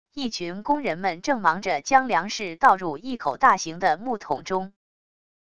一群工人们正忙着将粮食倒入一口大型的木桶中wav音频